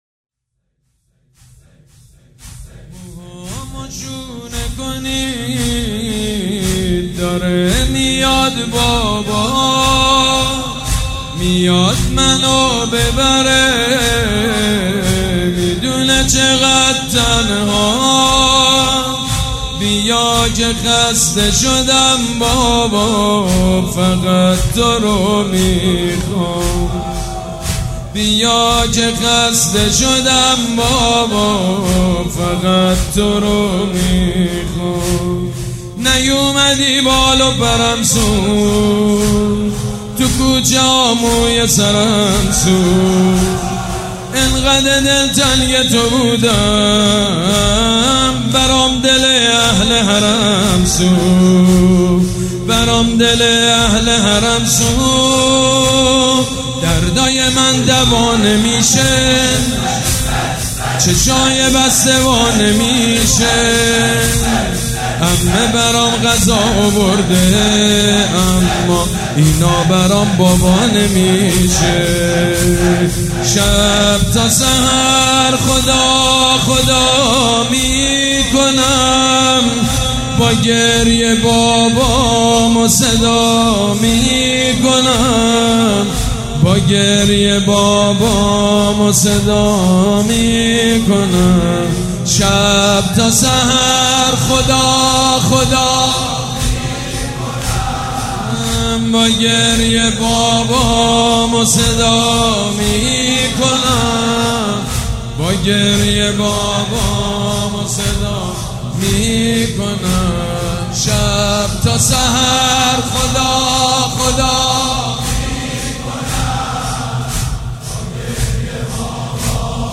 سید مجید بنی فاطمه؛ شب سوم محرم98